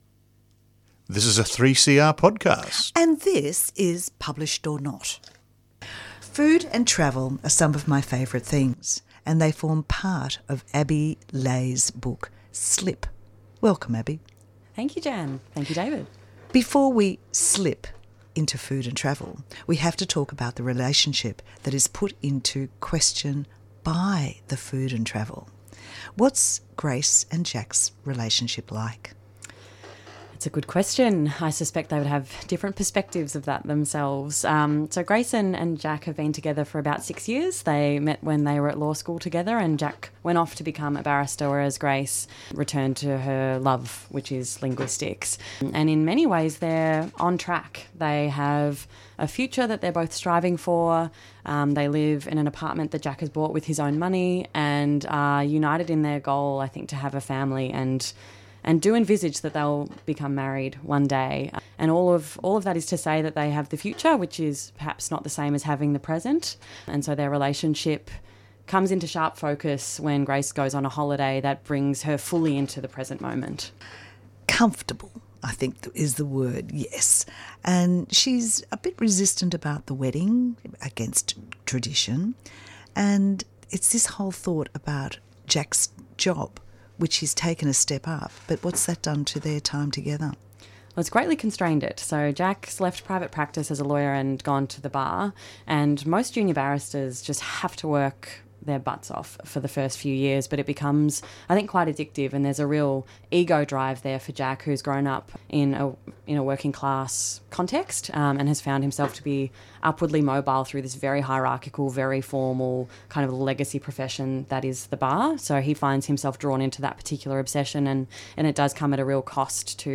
Tweet Published...Or Not Thursday 11:30am to 12:00pm Australian and international authors talk about their books and how they got published or how they self-published.